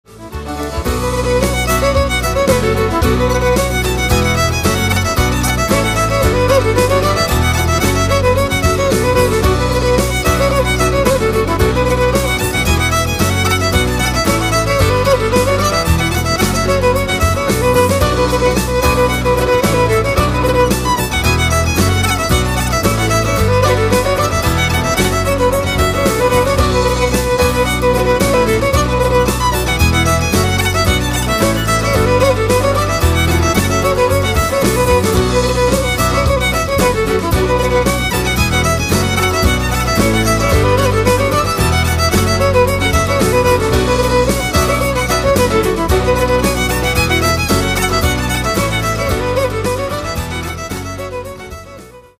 Predominantly a fiddle recording
Ten of the ttracks are fiddle-piano-guitar etc.